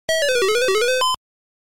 The sound that plays in Pengo when there's one Snow-Bee left.